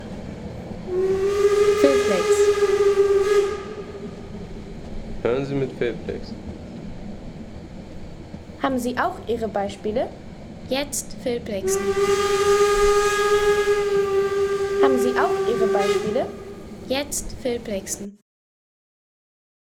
Lokpfeife